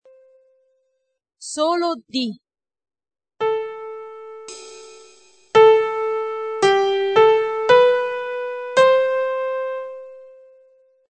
Qualora gli mp3 (di 2ª qualità per non appesantire il sito), di questa pagina, non fossero perfetti nell'ascolto, scriveteci, Vi invieremo sulla vostra casella di posta i file di 1ª qualità, gratuitamente.